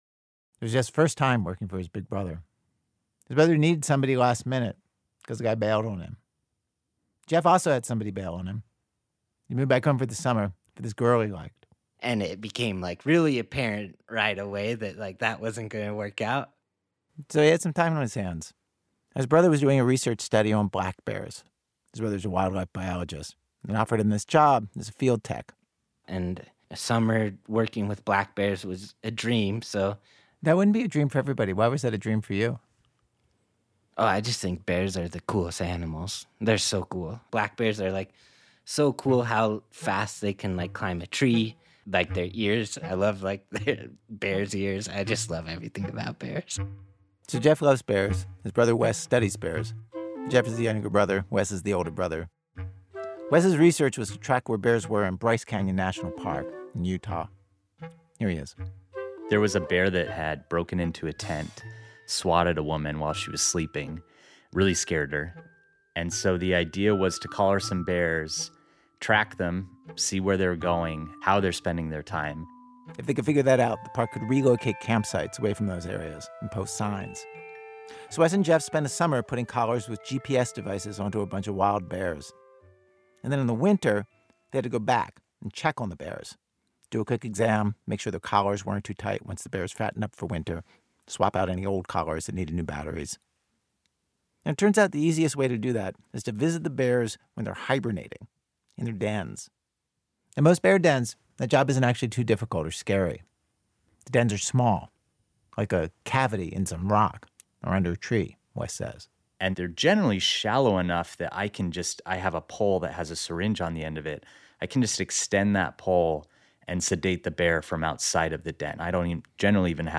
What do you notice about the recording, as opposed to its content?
Note: The internet version of this episode contains un-beeped curse words.